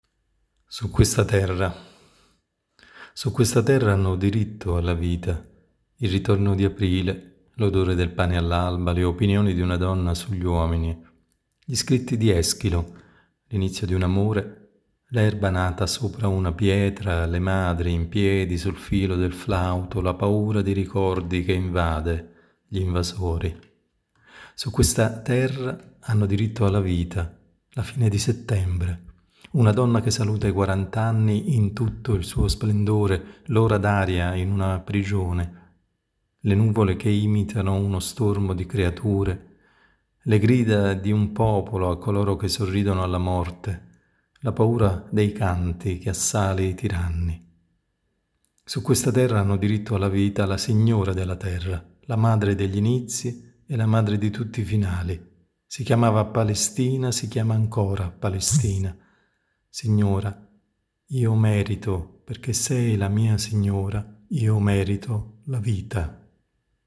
I bicchieri e le stelle, Lettura poesie, Poesie